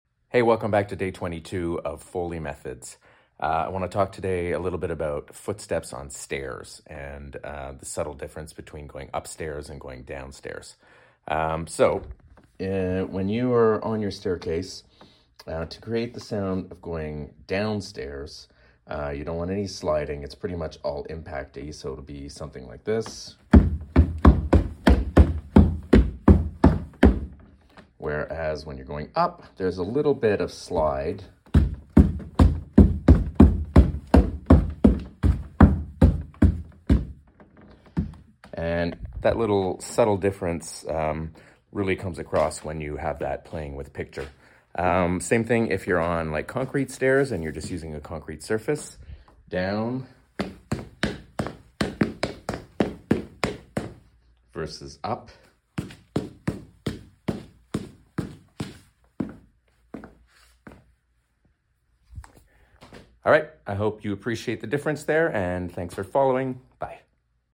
Day 22 of sharing some basic Foley artist techniques and info with you!